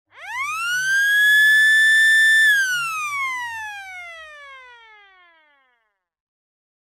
Sirena Super M SLD-0002
– Potencia de sonido a 1 m: hasta 127 dB(A)
– Frecuencia de sonido 1600 Hz (agudo)